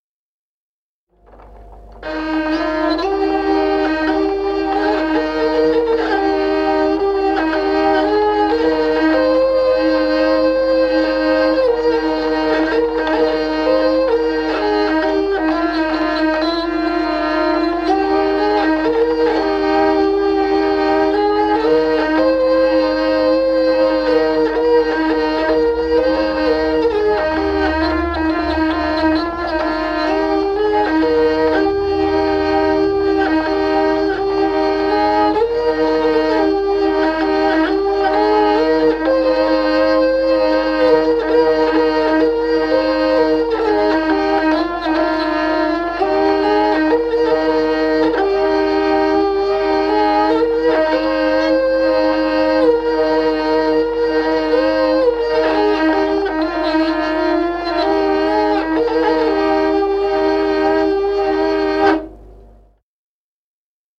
Народные песни Стародубского района «Бедные птички», духовный стих.
с. Мишковка.